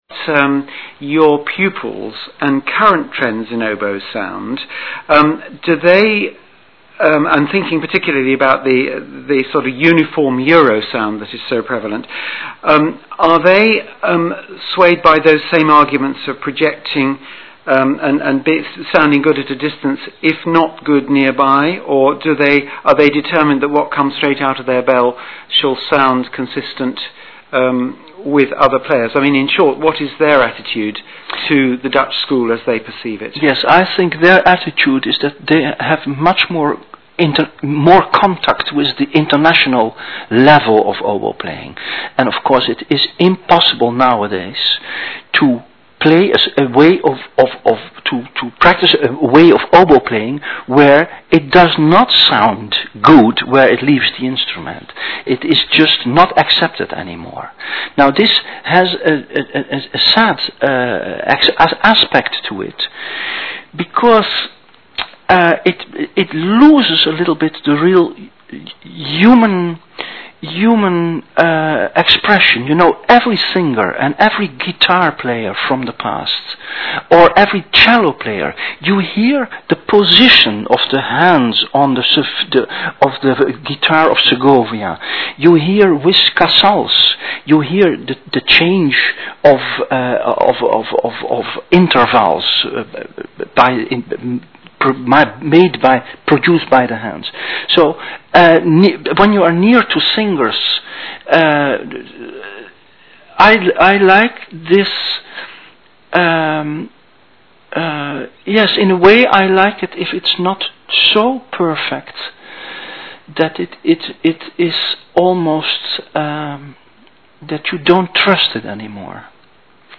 Interview with Han de Vries, including some MP3 sound clips